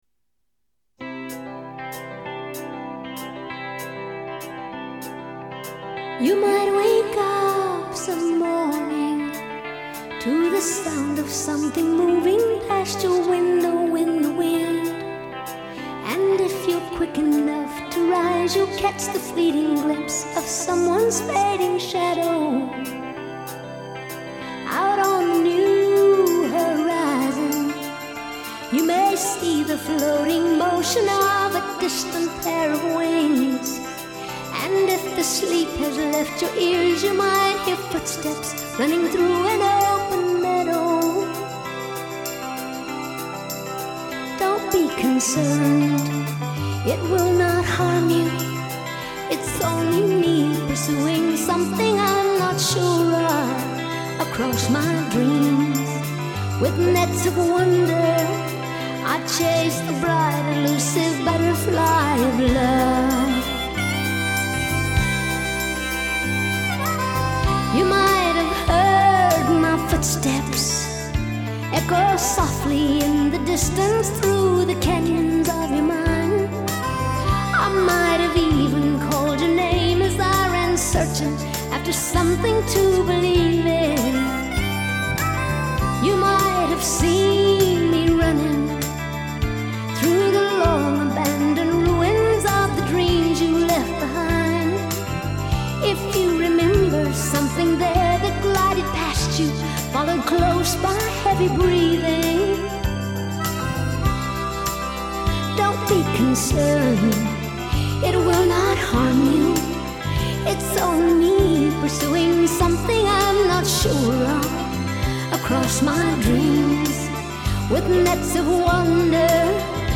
Жанр: Ballad, Folk, World, & Country, Pop Rock, Country Rock